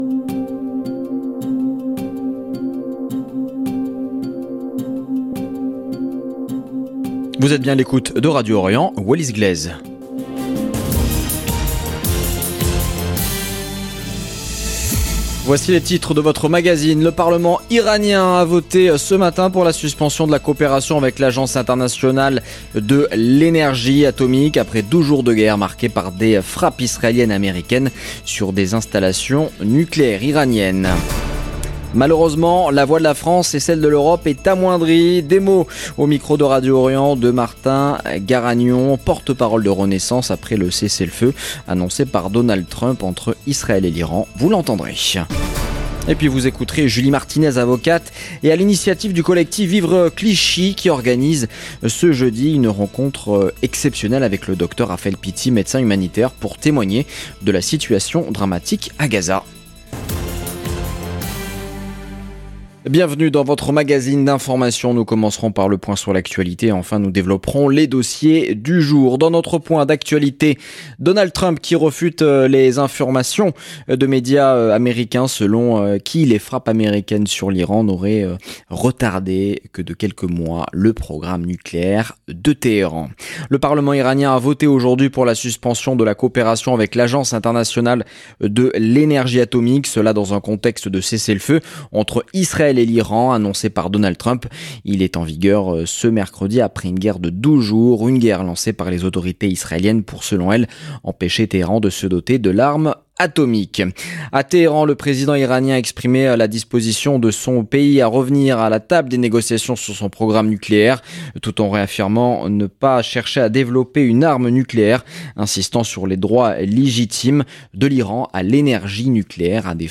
Magazine de l'information de 17H00 du 25 juin 2025